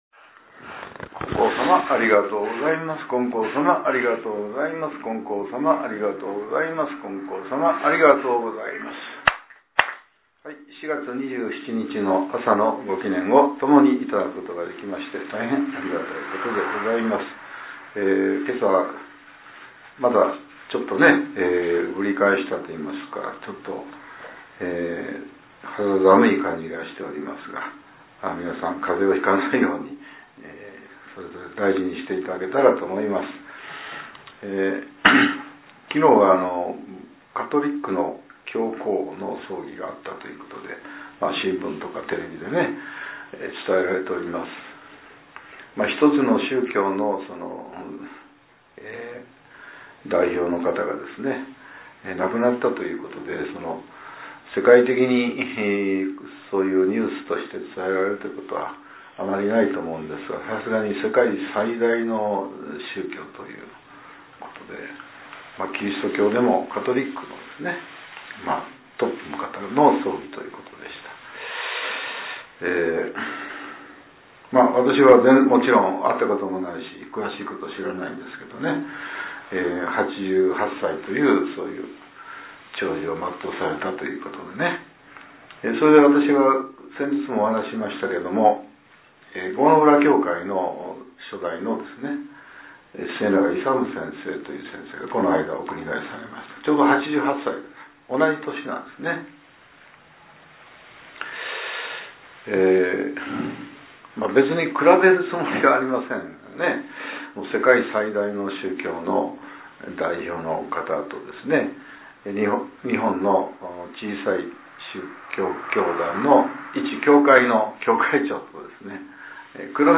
令和７年４月２７日（朝）のお話が、音声ブログとして更新されています。